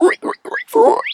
pig_2_hog_seq_11.wav